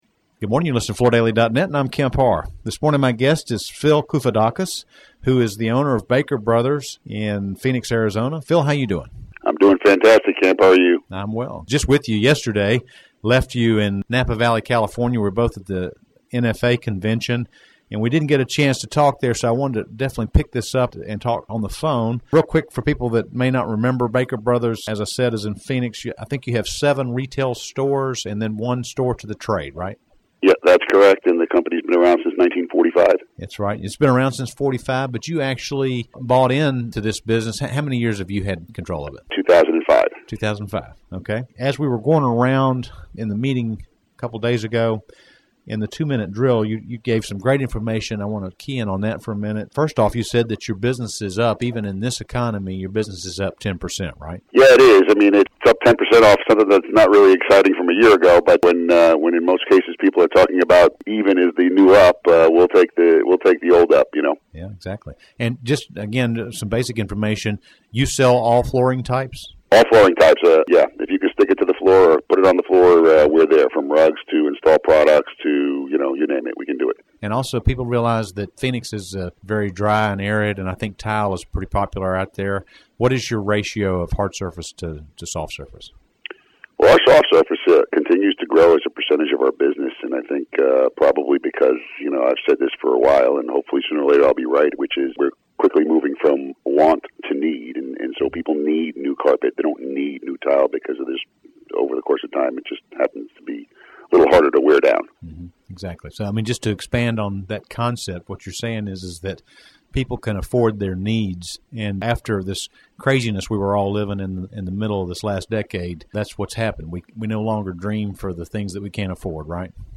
Listen to the interview to hear how this NFA member has grown in the past year.